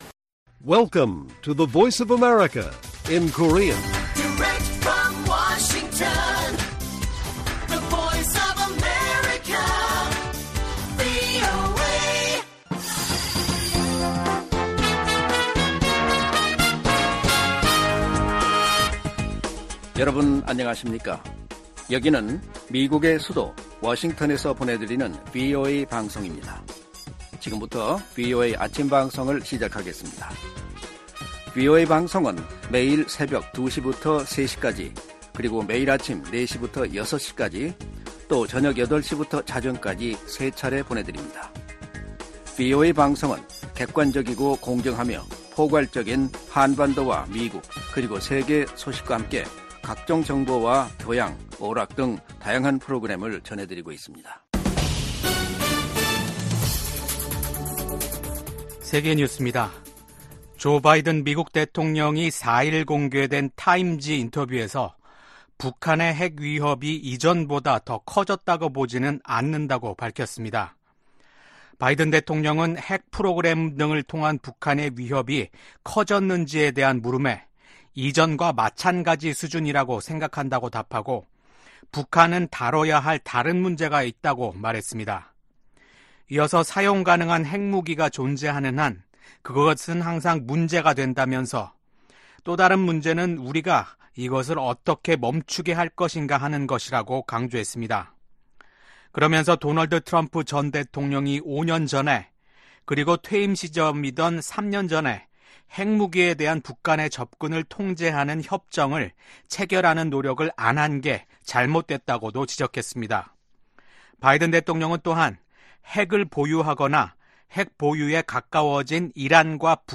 세계 뉴스와 함께 미국의 모든 것을 소개하는 '생방송 여기는 워싱턴입니다', 2024년 6월 5일 아침 방송입니다. '지구촌 오늘'에서는 세계 최대 인구 대국 인도의 총선 개표 소식 전해드리고, '아메리카 나우'에서는 조 바이든 대통령의 차남 헌터 바이든 씨의 총기 불법 소지 혐의에 관한 형사재판 이야기 살펴보겠습니다.